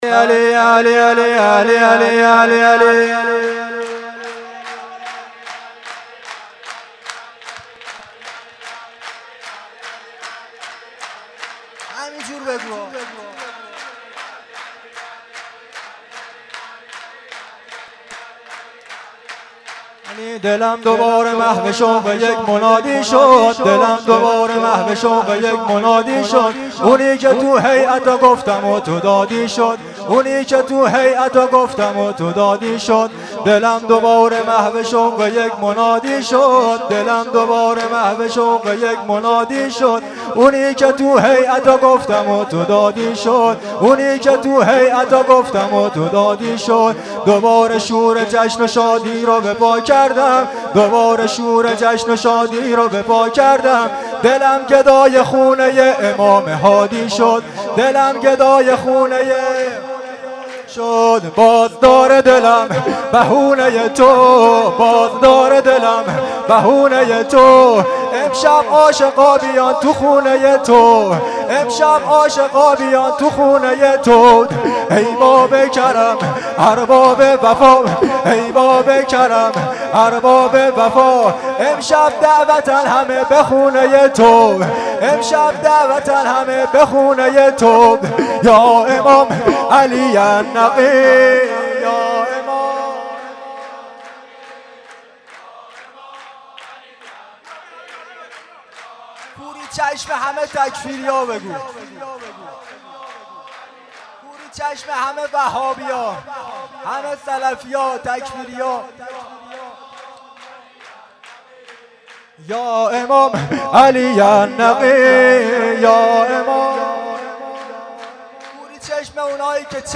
دلم دوباره محو شوق یک منادی شد(سرود در مدح امام هادی(ع.mp3